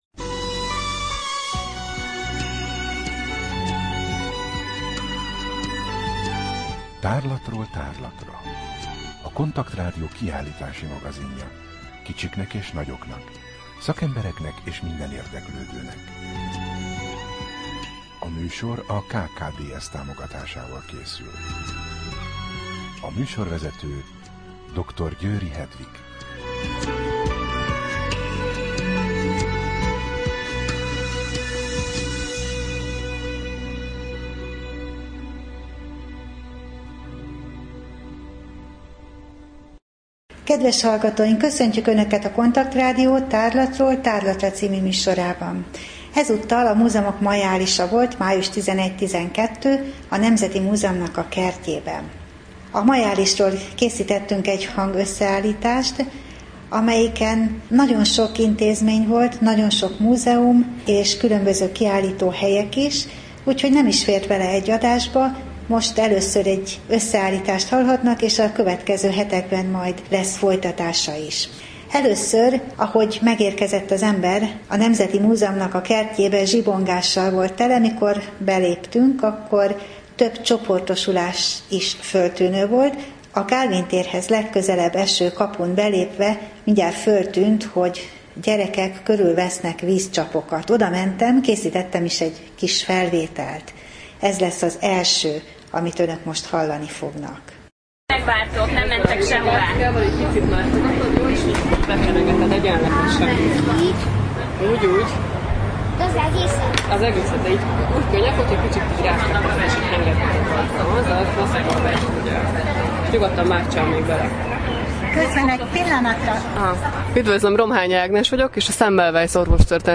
Rádió: Tárlatról tárlatra Adás dátuma: 2013, Május 13 Tárlatról tárlatra / KONTAKT Rádió (87,6 MHz) 2013. május 13.